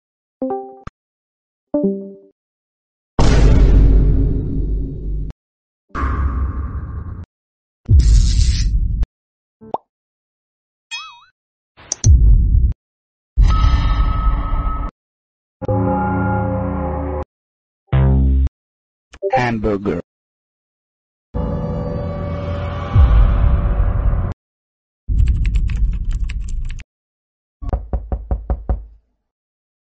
Beluga Sound Effect Free Download
Beluga